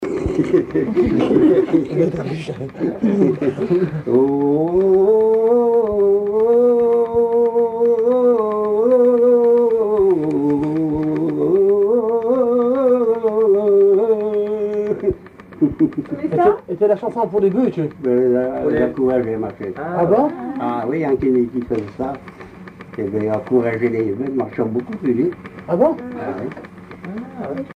bouvier ; chanteur(s), chant, chanson, chansonnette
Appels de labour, tiaulements, dariolage, teurlodage, pibolage
couplets vocalisés